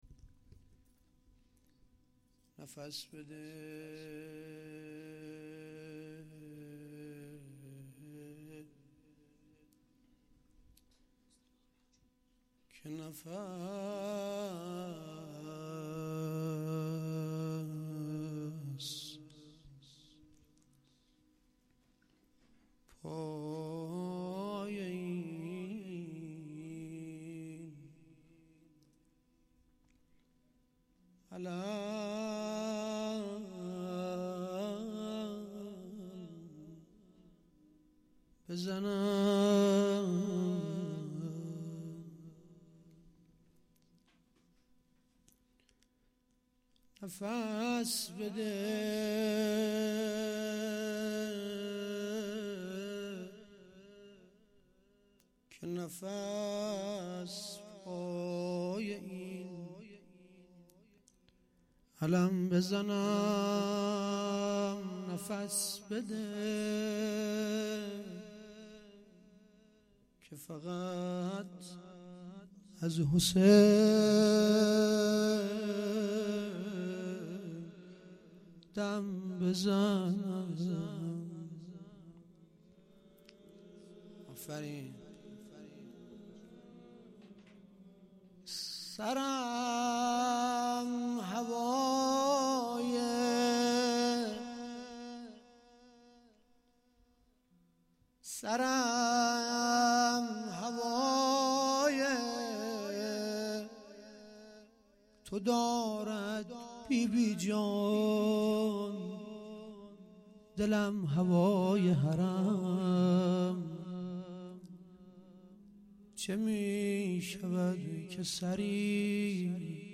roze.mp3